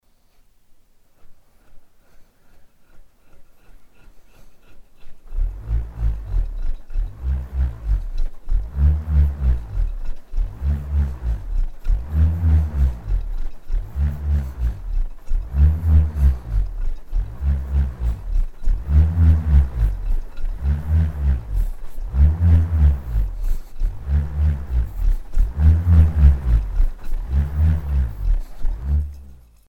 Bullroarer Acoustics
Bullroarer.mp3